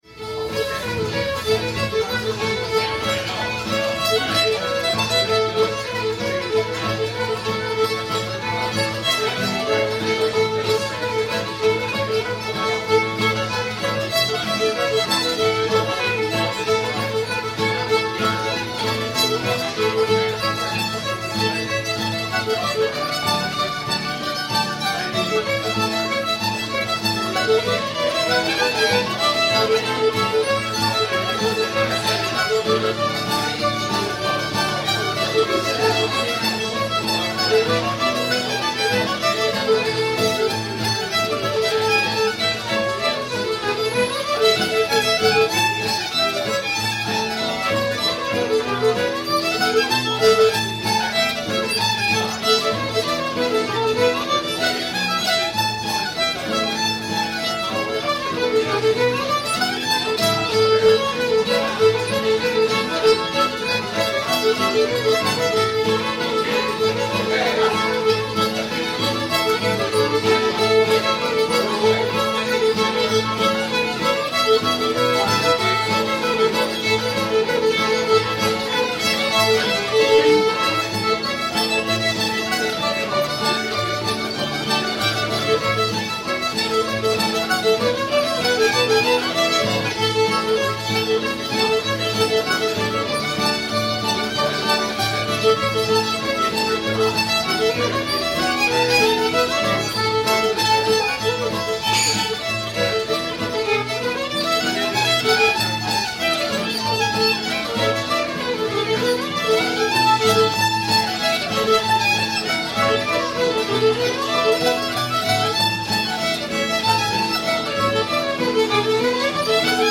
three thin dimes [A]